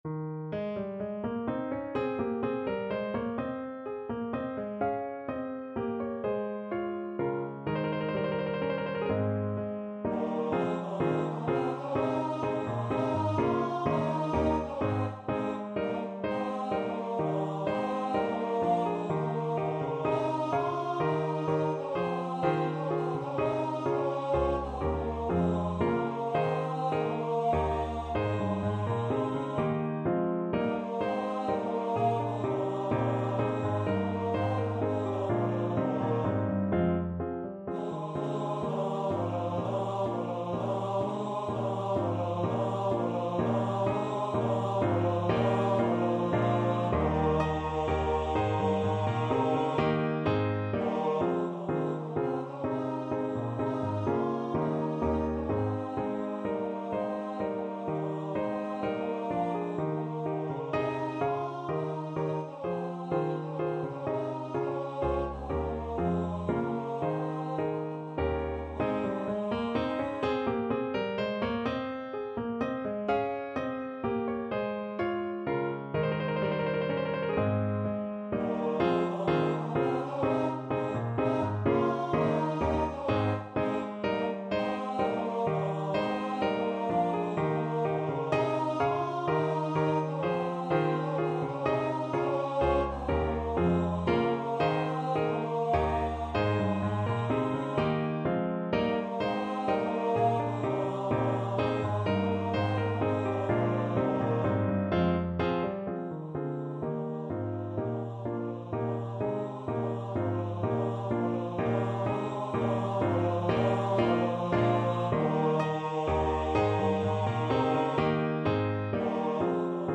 3/4 (View more 3/4 Music)
Allegro giusto =126 (View more music marked Allegro)
Classical (View more Classical Tenor Voice Music)